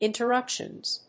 /ˌɪntɝˈʌpʃʌnz(米国英語), ˌɪntɜ:ˈʌpʃʌnz(英国英語)/